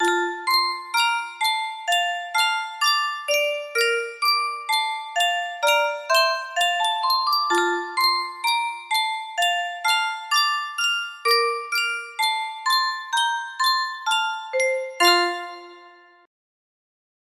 Yunsheng Custom Tune Music Box - Japanese Song music box melody
Full range 60